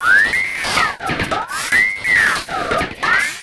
E Kit 13.wav